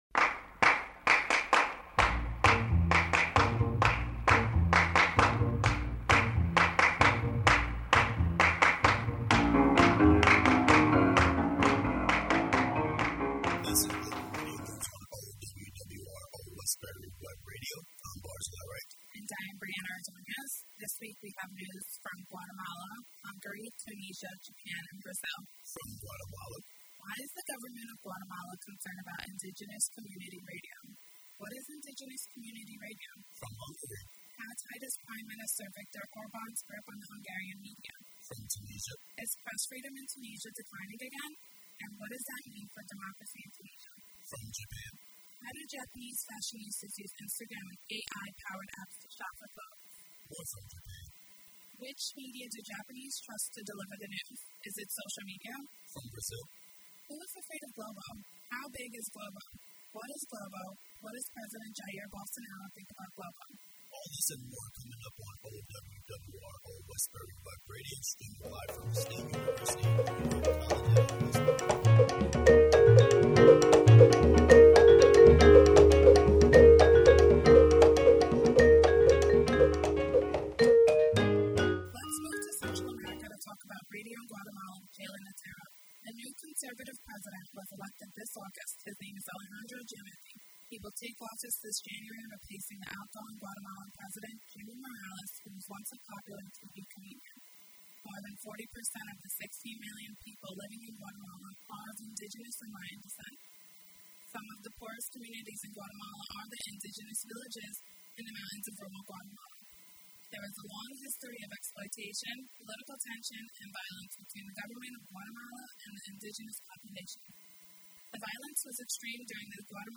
Global Media News is a weekly webcast covering media news from around the world. GMN is a production of Media Studies students from the SUNY College @ Old Westbury.